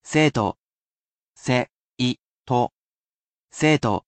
I will sound out each character for you and tell you the pronunciation.
And, I will never become bored of saying said word, as I am a computer robot, and repetitive tasks are my [ｉｎｓｅｒｔ　ｌｏｃａｌ　Ｅａｒｔｈ　ｗｉｎｔｅｒ　ｈｏｌｉｄａｙ].